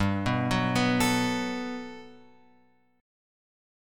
G Major 9th